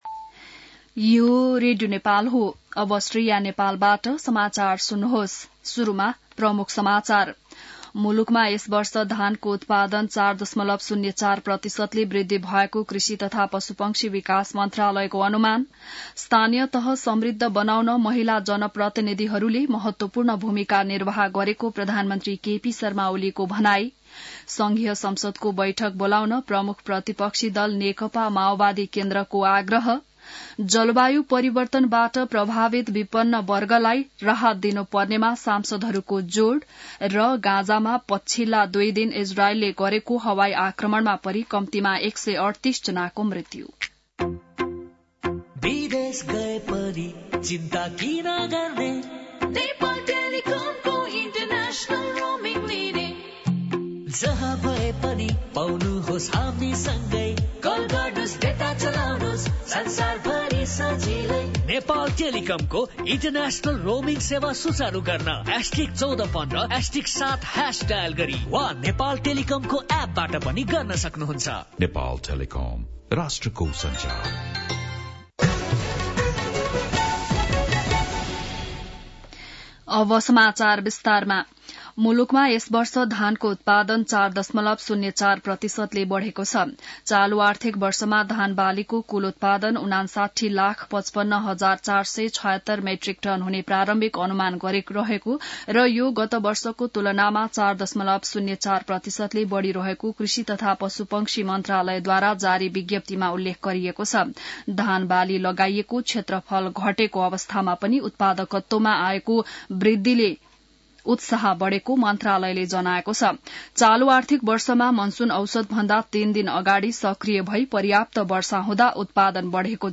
बिहान ७ बजेको नेपाली समाचार : २१ पुष , २०८१